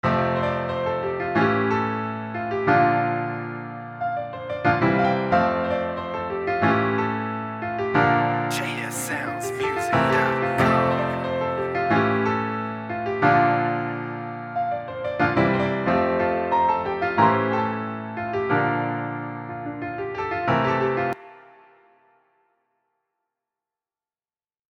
100% Original Piano Loop
Tags: loops , piano , samples